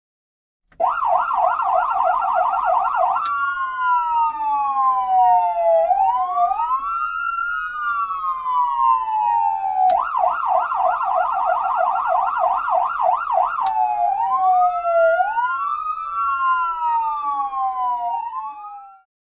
ambulance.mp3